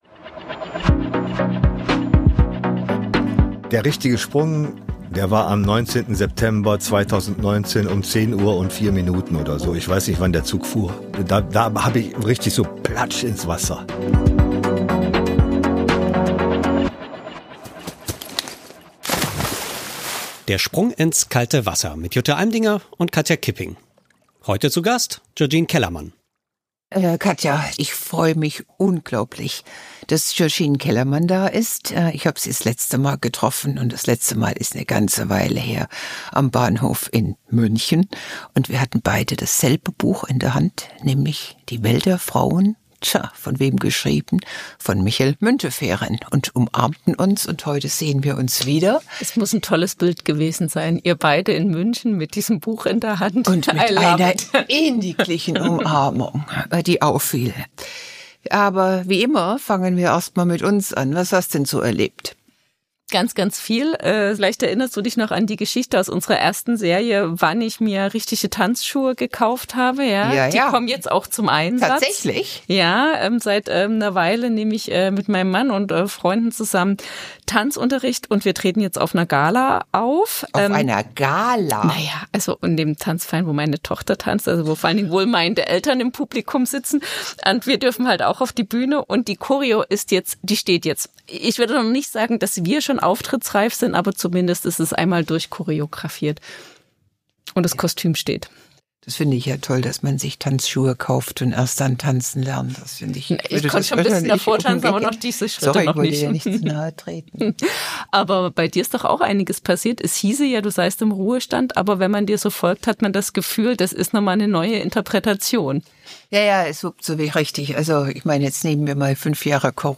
sprechen mit Georgine Kellermann über ihr Coming-out als trans Frau, über alte Rollenmuster und über den Mut, sich nicht länger zu verstecken.